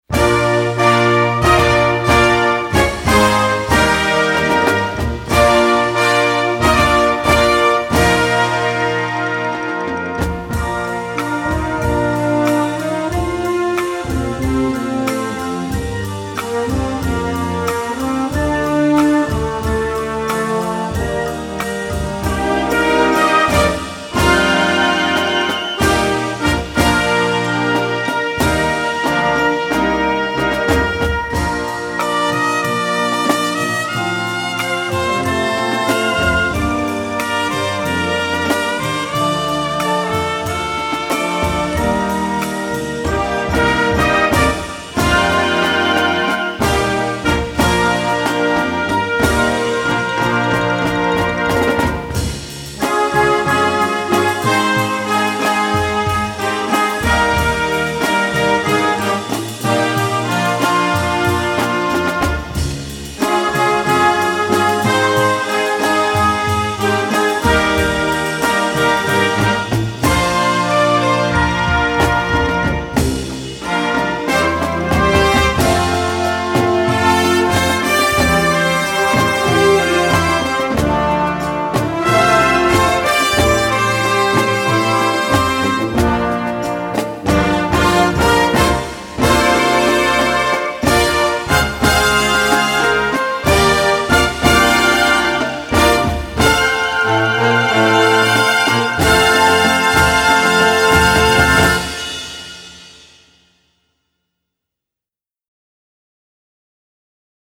Orchestre D'Harmonie